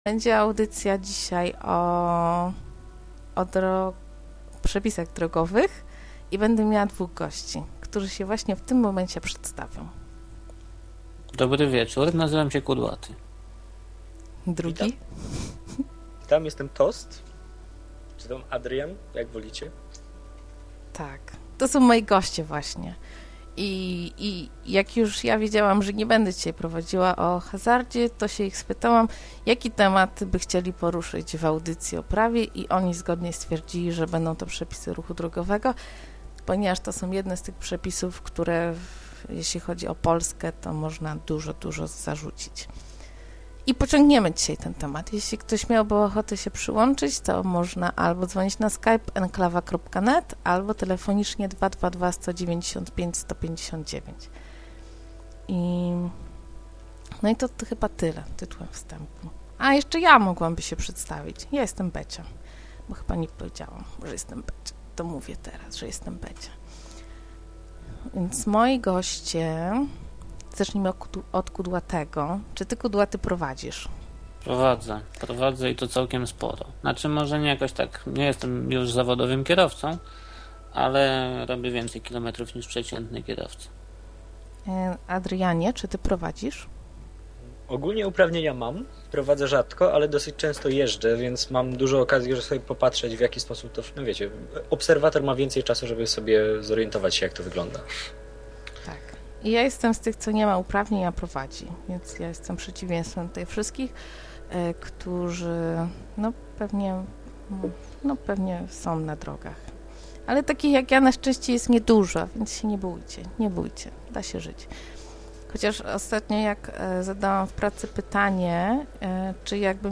byli dwaj goście i rozmawialiśmy jak kierowca z kierowcą. o pord i wypadkach. kilka brutalnych opisów katastrof w ruchu drogowym.